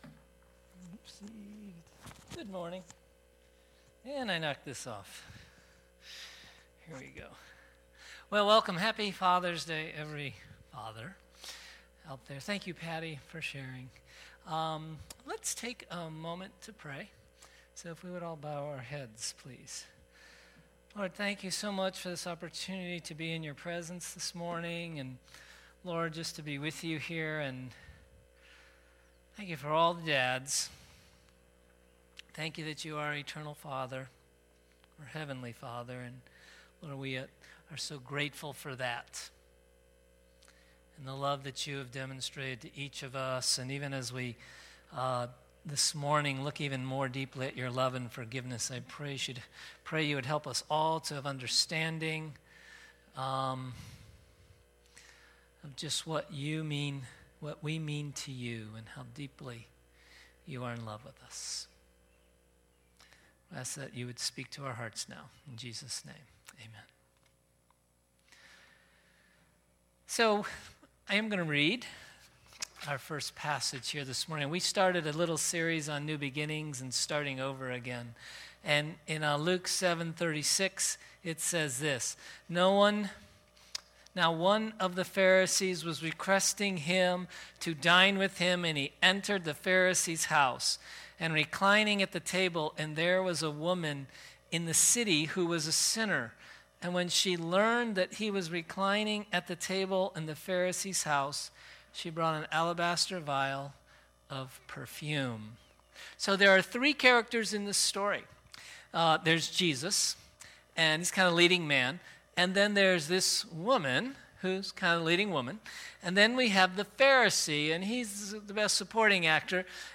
Grace Summit Community Church | Cuyahoga Falls, Ohio